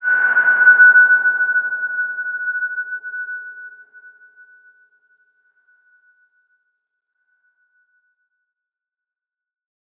X_BasicBells-F#4-pp.wav